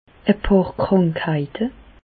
Prononciation 67 Reichshoffen